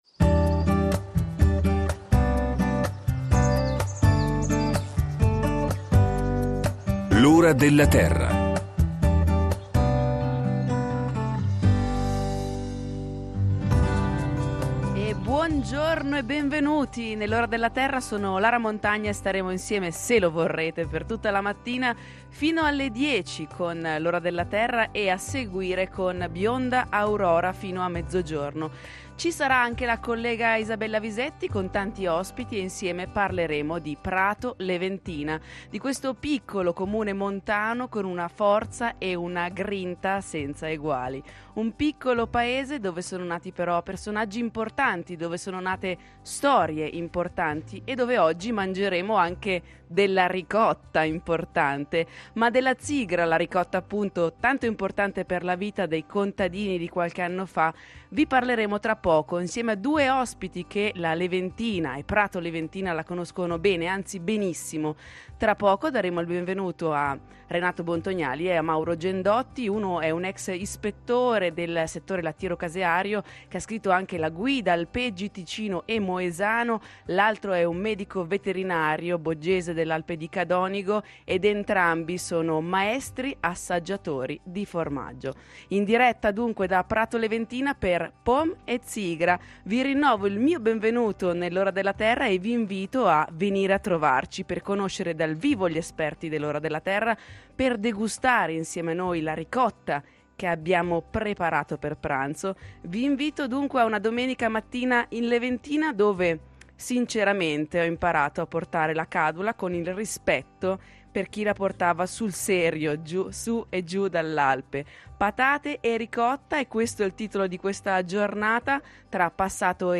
In diretta dalla partenza dello Sci Lift di Prato Leventina dalle 9.00 alle 12.00 “ Züfata popolare ” le tradizioni della Leventina raccontate dalle memorie storiche della valle, con uno sguardo ai prodotti nostrani, agli alpeggi e al palato.